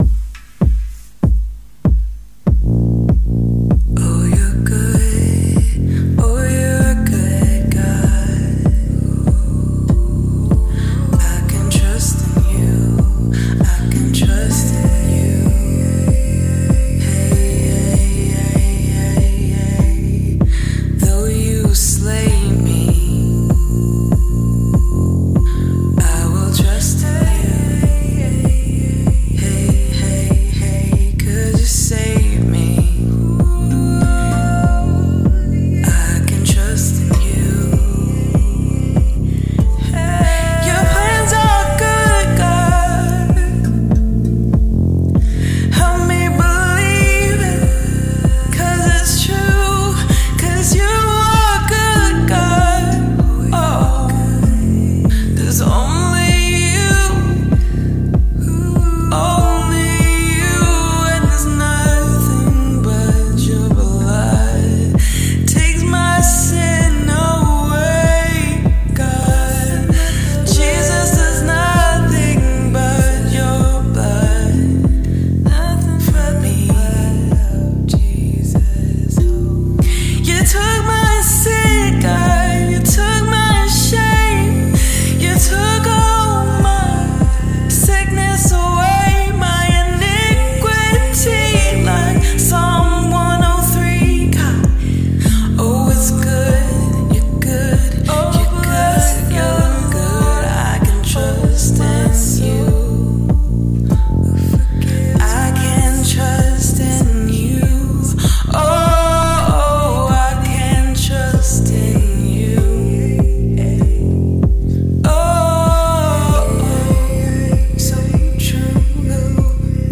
improvised worship
Rav vast drum
spontaneous worship # tongue drum